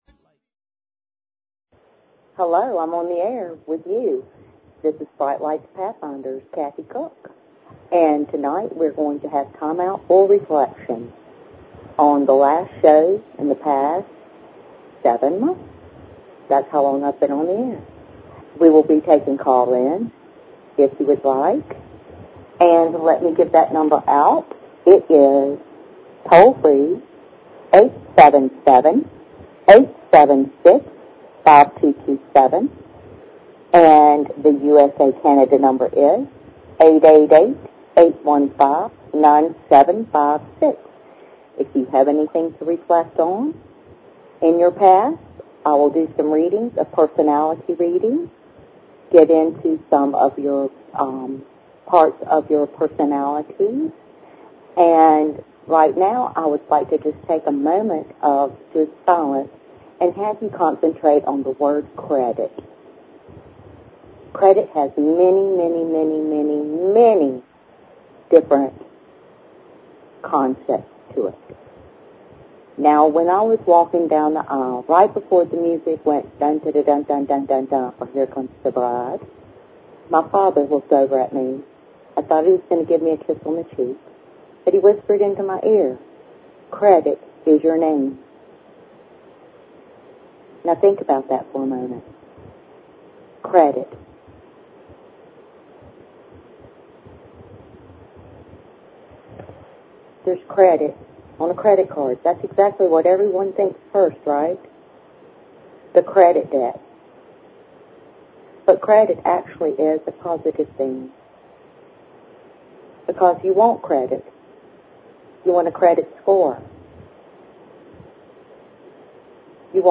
Talk Show Episode, Audio Podcast, Brightlights_Pathfinders and Courtesy of BBS Radio on , show guests , about , categorized as
YOUR CREDIT IS YOUR NAME What else is Credit? Hand shake Word Written word Listen up- Tune in though mid way its a bit muffeled it is worth the strain one of my best by far!